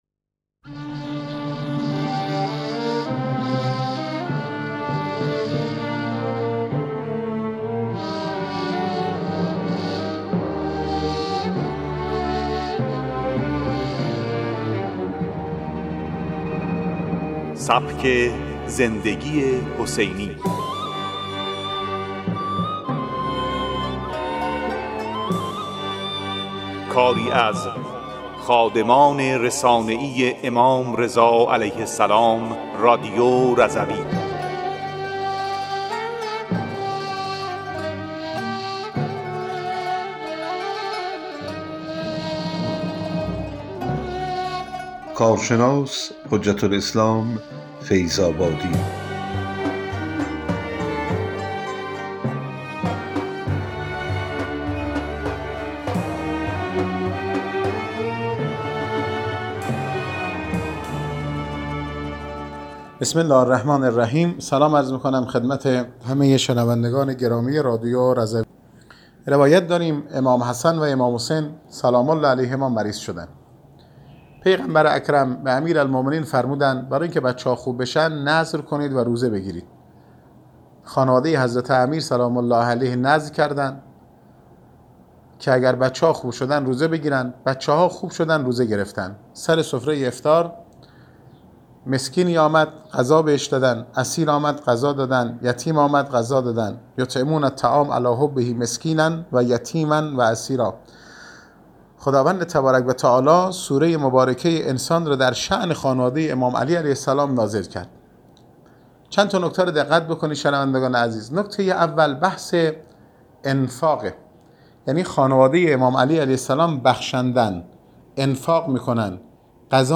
ویژه‌برنامه سبک زندگی حسینی حاصل تلاش خادمان رسانه‌ای امام رضا علیه‌السلام در گروه رادیو رضوی با همکاری کانون‌های خدمت رضوی استان مازندران است.